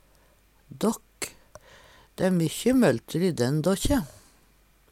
dåkk - Numedalsmål (en-US)